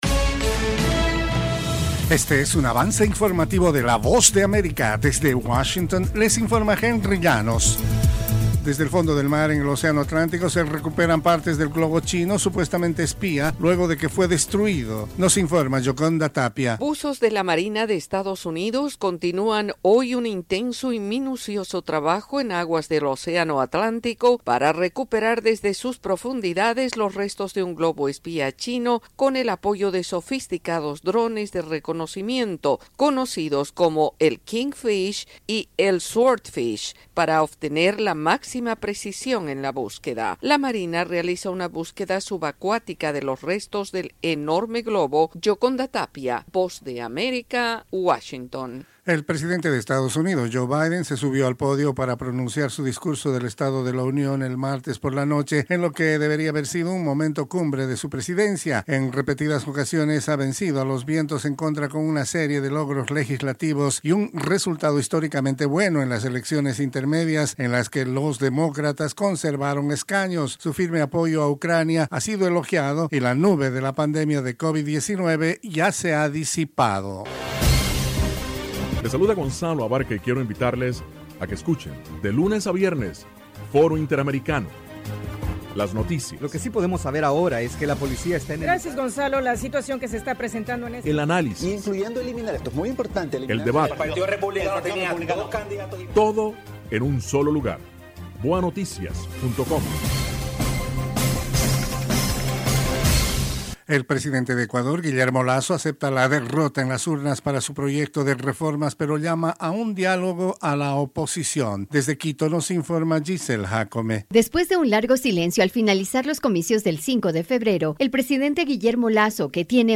Cápsula informativa de tres minutos con el acontecer noticioso de Estados Unidos y el mundo. [11:00am Hora de Washington].